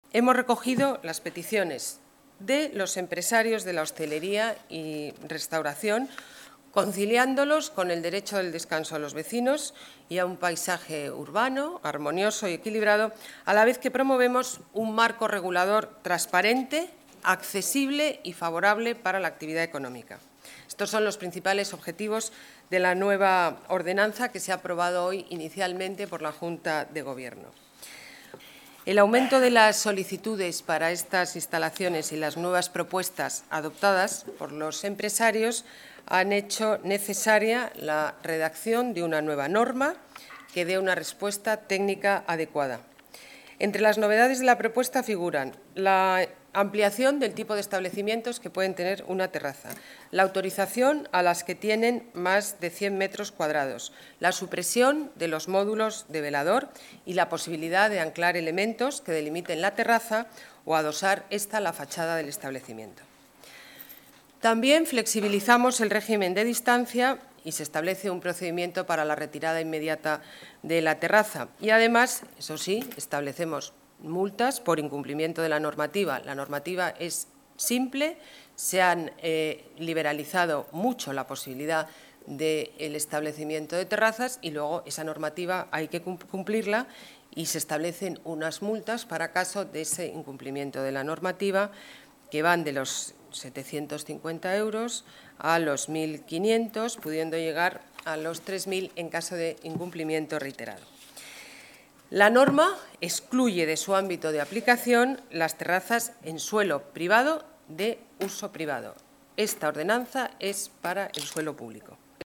Nueva ventana:Declaraciones de la alcaldesa, Ana Botella, sobre la nueva ordenanza de terrazas